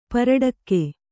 ♪ paraḍakke